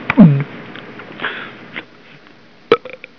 gulpburp.wav